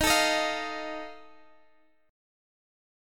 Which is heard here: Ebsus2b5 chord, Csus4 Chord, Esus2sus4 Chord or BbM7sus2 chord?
Ebsus2b5 chord